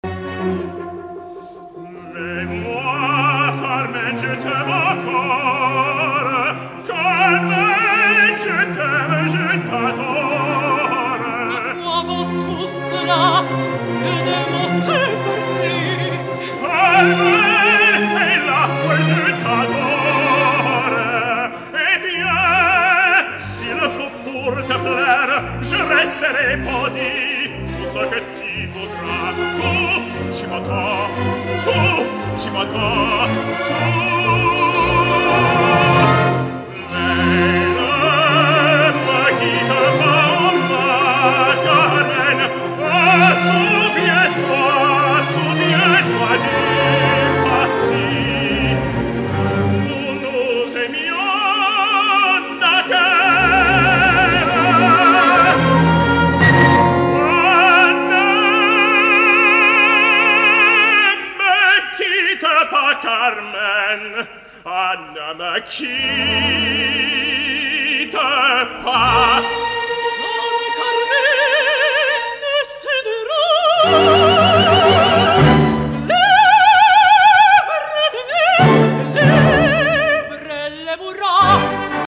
L’escoltarem en àries prou conegudes, del gran repertori i en una gravació provinent d’un concert en directa, per tant podreu apreciar les virtuts d’una veu in un cantant, de la mateixa manera que evidenciareu que no tot està assolit i que encara queden coses per polir.
mezzosoprano
on podreu apreciar aquesta veu amb tonalitats bronzines que jo crec que el situa en una vocalitat més heroica que no pas lírica.
Tots els fragments provenen d’un concert celebrat el dia 9 de gener de 2013 a Jerusalem, l’orquestra està dirigida per Frédéric Chaslin